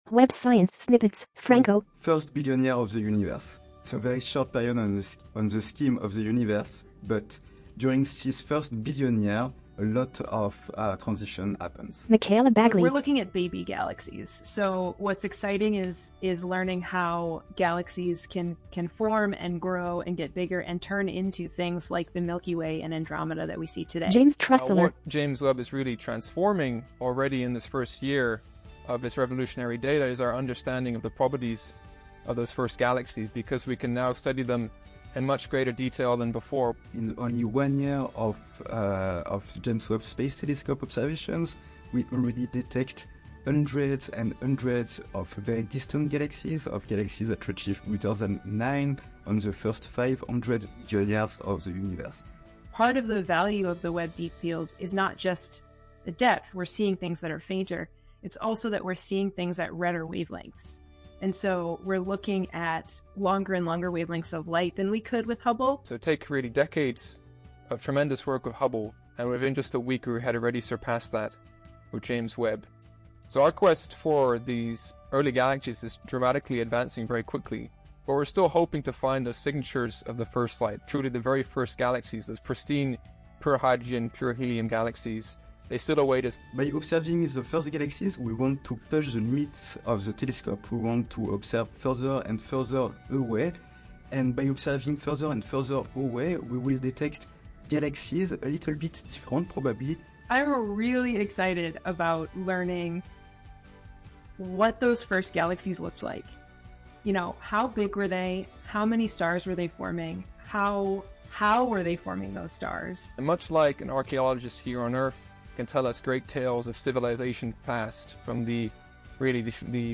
Music: “You Got This” by Reveille, courtesy of Soundstripe.
• Audio Description